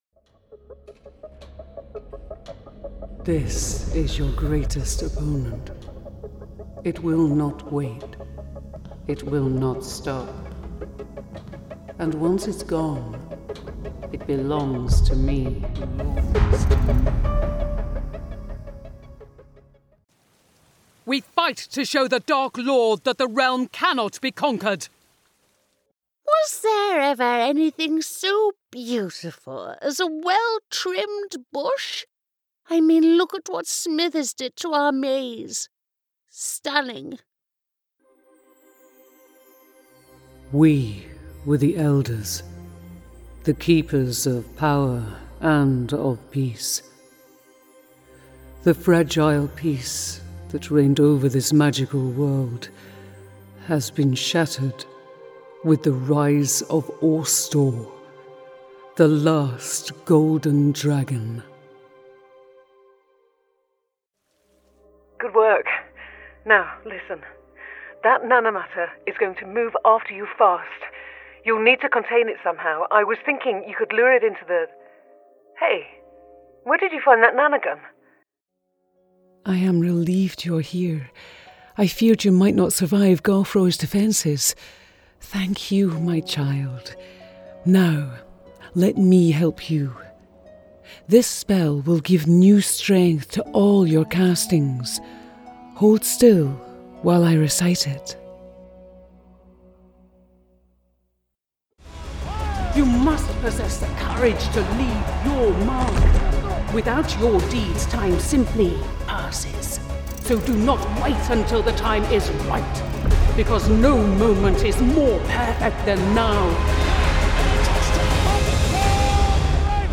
Female
Adult (30-50), Older Sound (50+)
Strong, empathic, business-like, authoritative, gentle, sexy and believable. Her native accent is Northern British, with an excellent Standard British Accent as well as many other regional accents
Home studio With Neumann TLM 103, Soyuz 1973.
Video Games
Words that describe my voice are Natural British Voice, Authoritative, Husky.